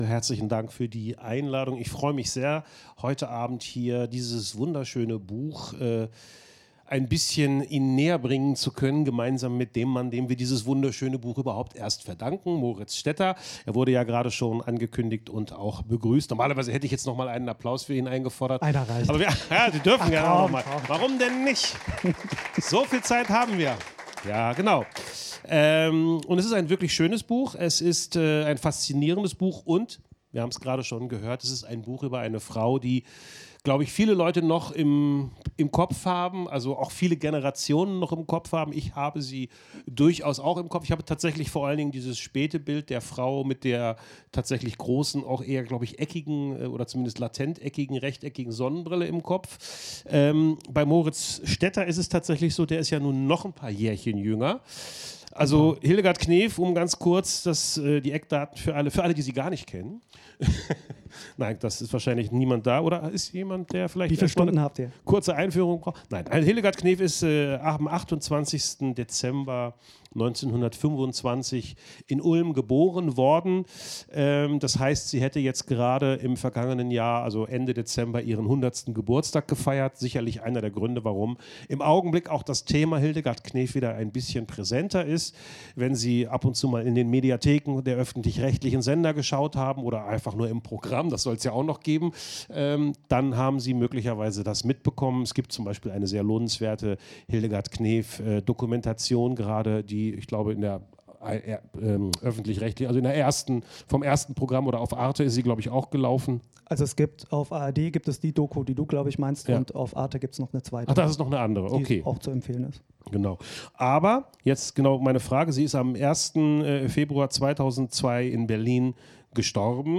Gespräch
Veranstaltungen Stadtbibliothek Stuttgart